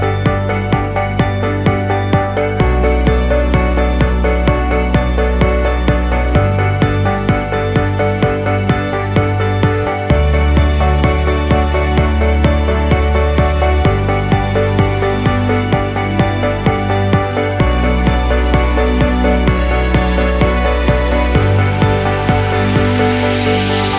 No Backing Vocals Dance 3:28 Buy £1.50